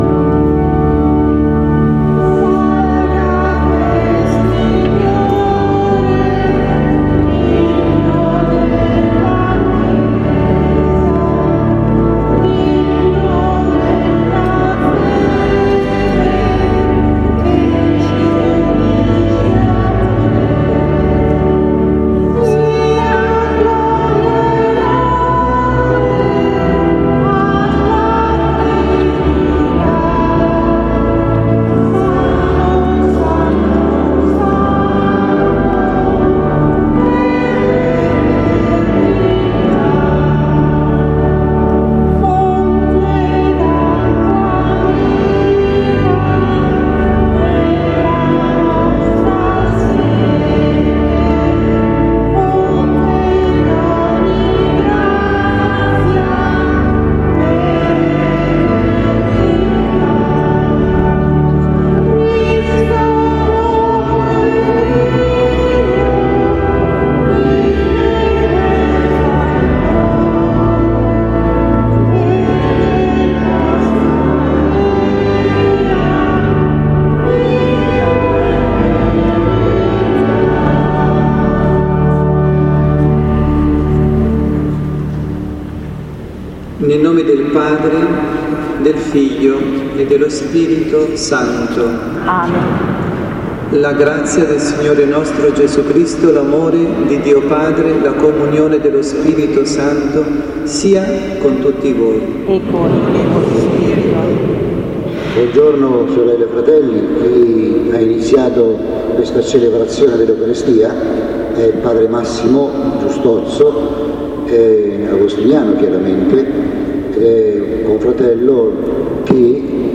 dalla Parrocchia Santa Rita – Milano